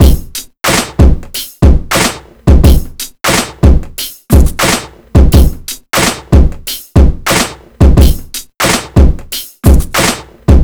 • 90 Bpm Drum Loop C# Key.wav
Free drum groove - kick tuned to the C# note. Loudest frequency: 1807Hz
90-bpm-drum-loop-c-sharp-key-QHV.wav